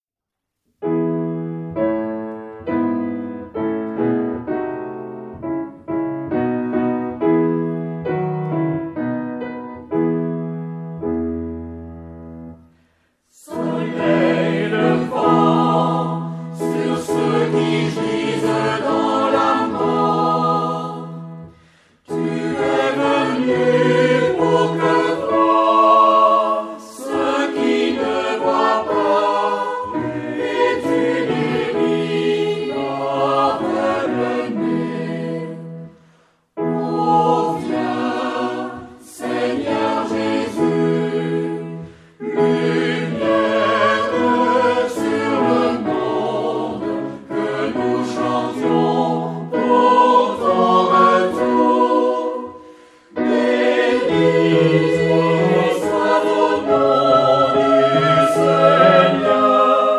Genre-Style-Form: Sacred ; Hymn (sacred)
Type of Choir: SATB  (4 mixed voices )
Tonality: E major